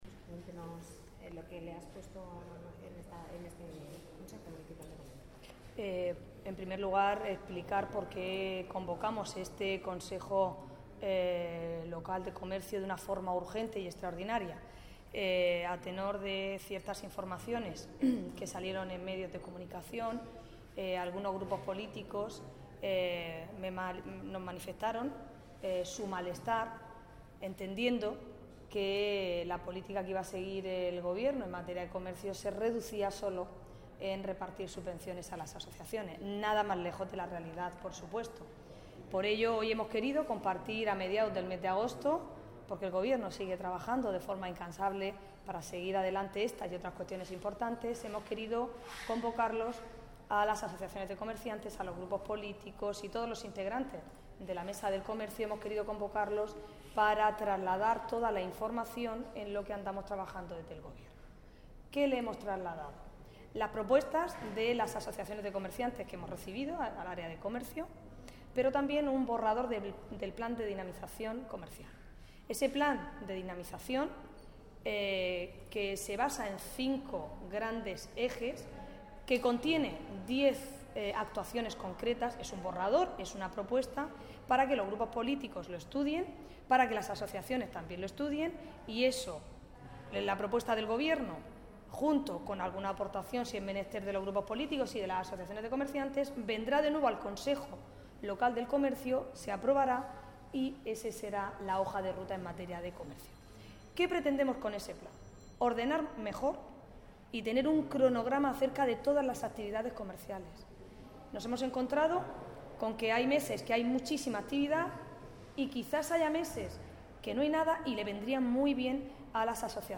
El documento ha sido expuesto esta mañana a comerciantes y grupos municipales en el Consejo Municipal celebrado en el Palacio Consistorial